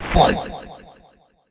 snd_boxing_fight_bc.wav